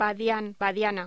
Locución: Badián, badiana